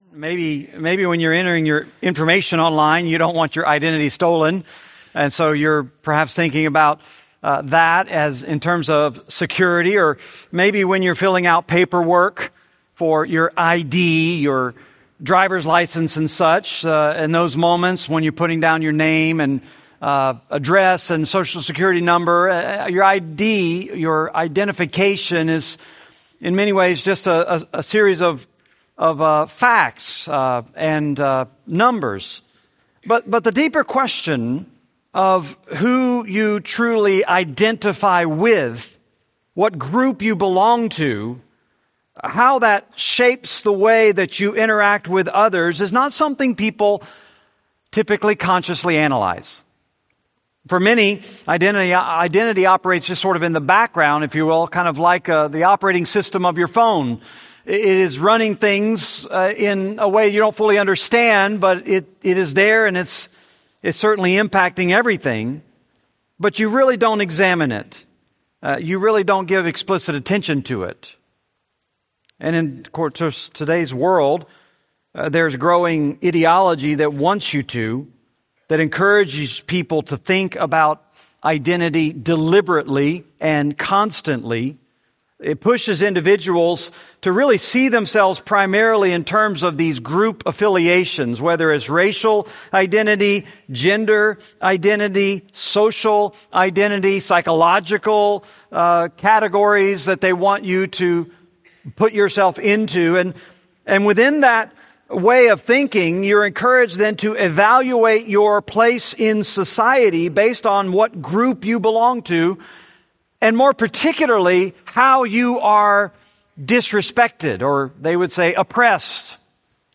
Series: Benediction Evening Service, Bible Studies